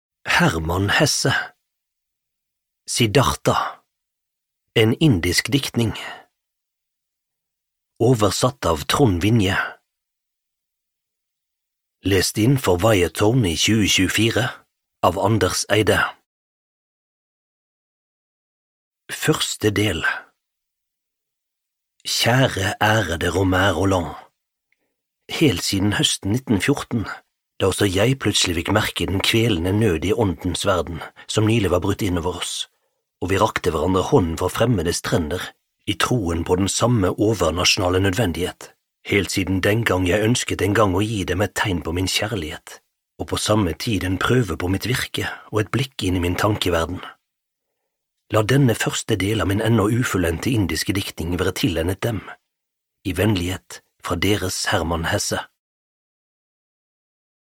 Siddhartha - en indisk diktning (lydbok) av Hermann Hesse